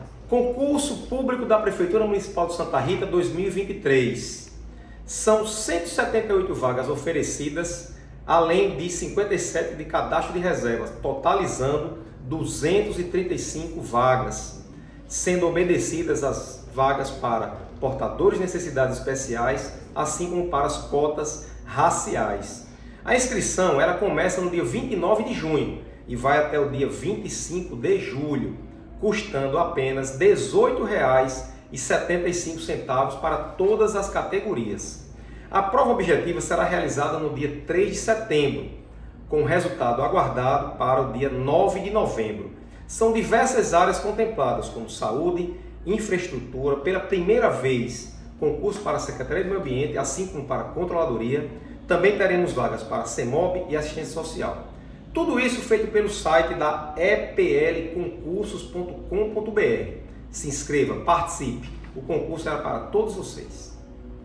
Ouça o anúncio feito pelo prefeito: